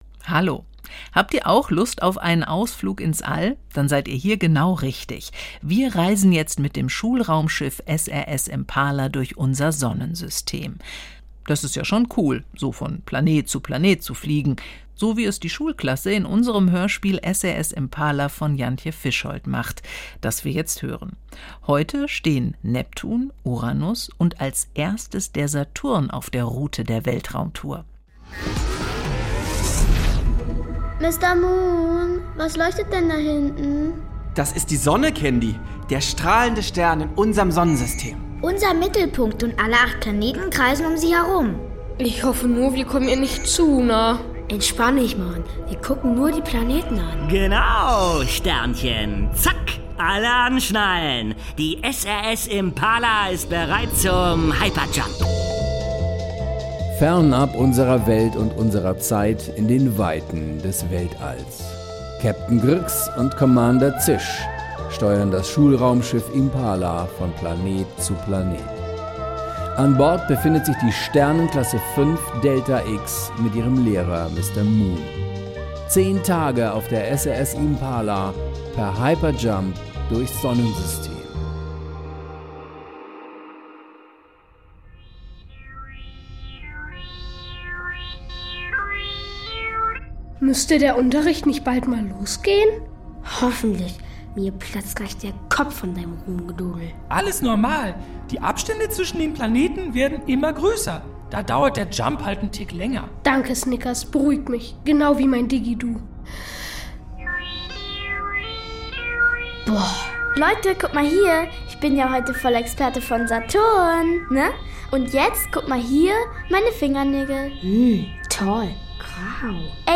Kinderhörspiel-Podcast: SRS Impala 7-9 ~ Hörspiele, Geschichten und Märchen für Kinder | Mikado Podcast